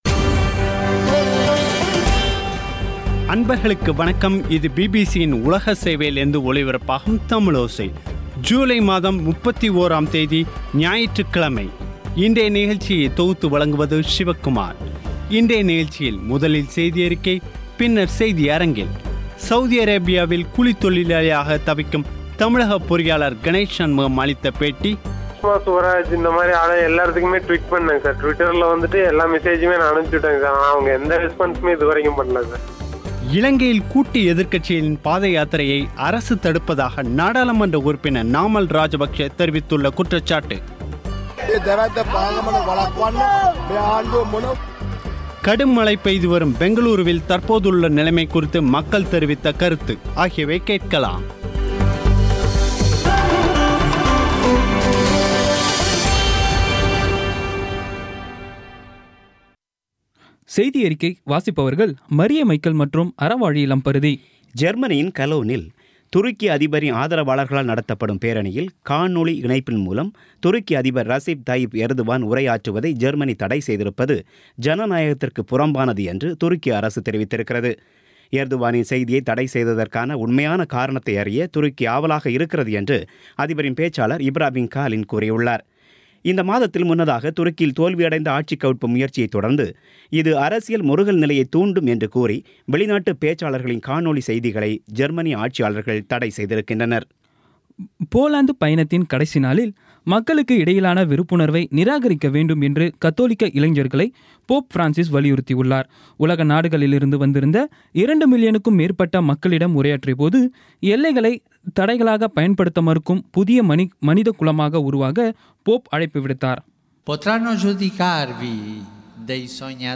இன்றைய நிகழ்ச்சியில் முதலில் செய்தியறிக்கை